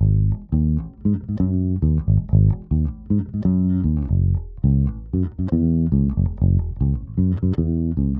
04 Bass PT3.wav